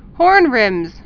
(hôrnrĭmz)